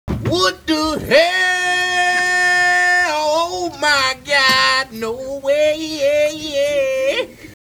Funny sound effects
what_the_hell.wav